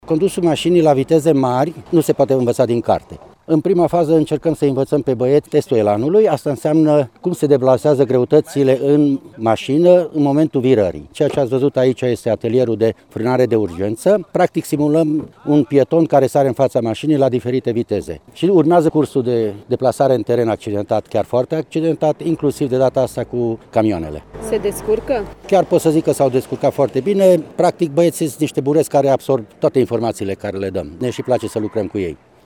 Instuctor